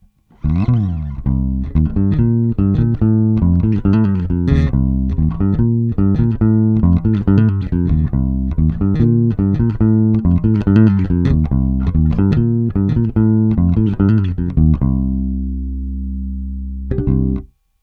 ベースラインでこの曲なーんだ
今月のベースラインはこちら！！